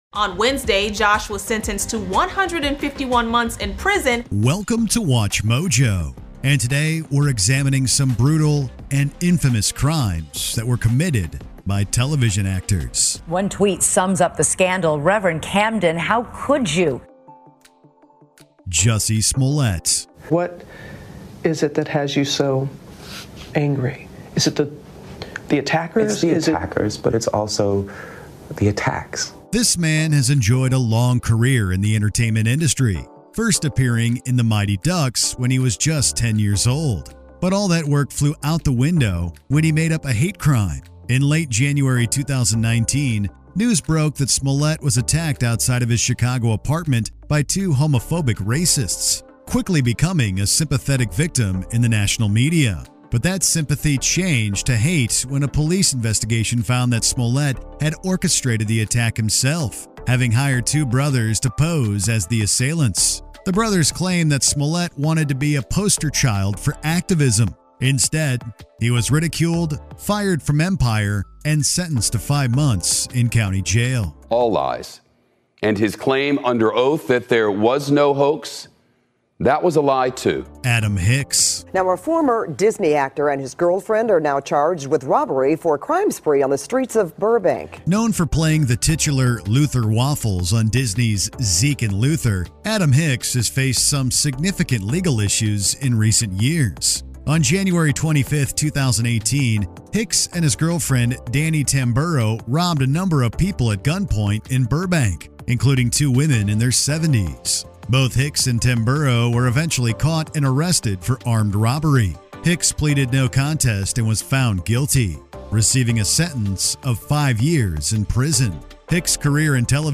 English - USA and Canada
Middle Aged